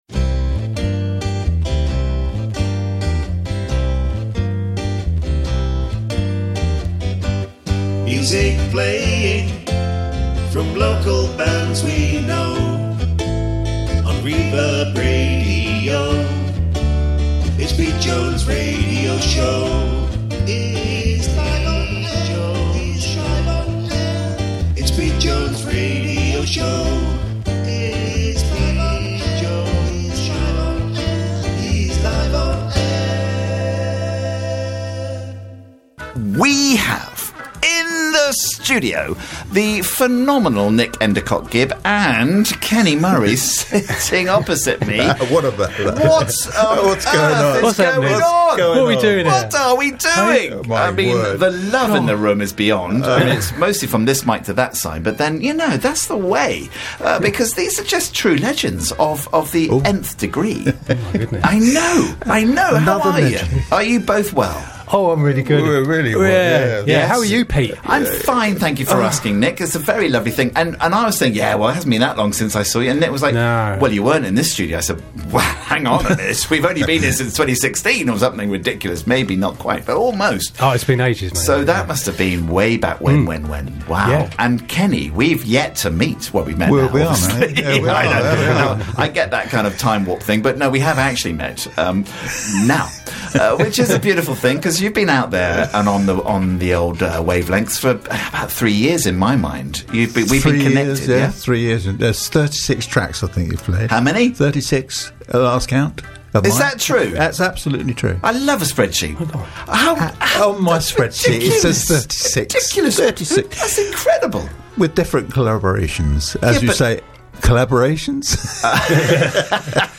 PLEASE check him out for your creative needs. 3 studio tracks played out.